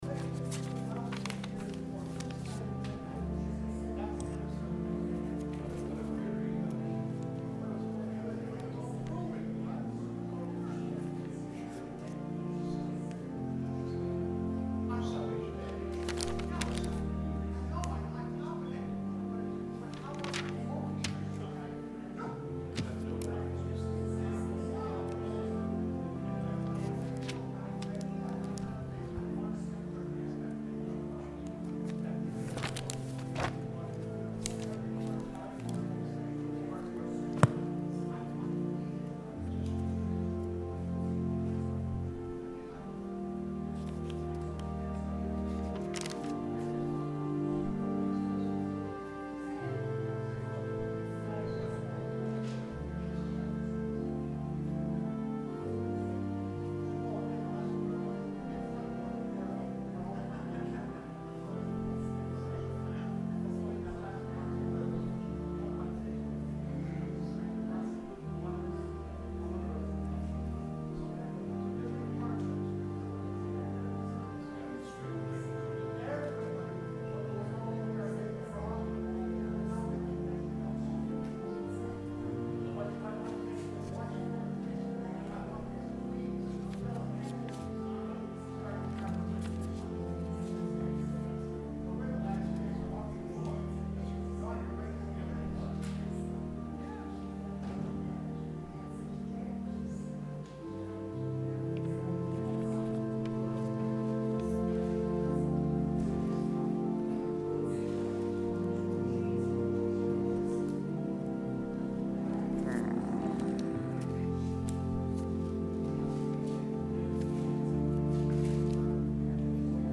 Sermon – August 11, 2019